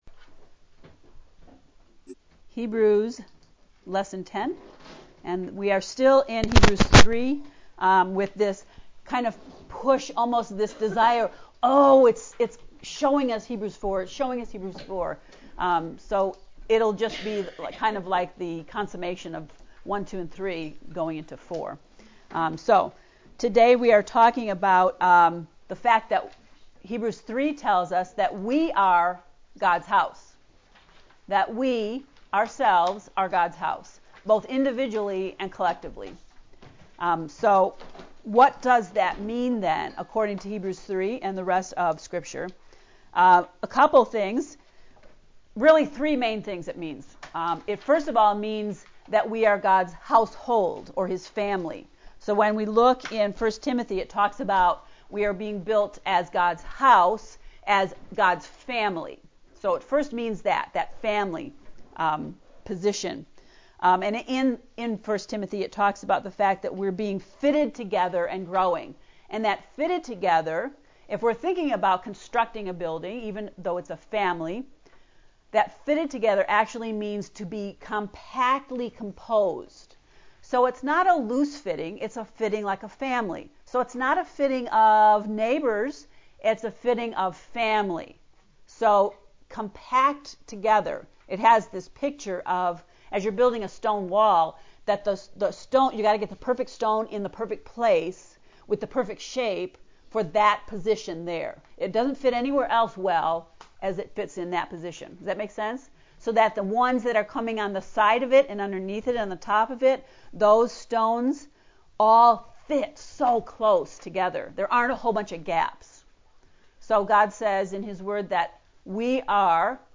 to listen to Hebrews lesson 10 lecture “House Hunters” please click below:
heb-lecture-10.mp3